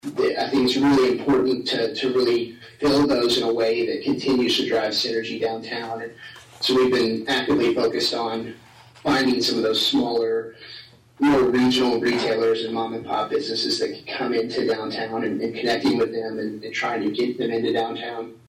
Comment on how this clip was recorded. appeared via zoom at Wednesday’s Atlantic City Council meeting and provided an update on business recruitment.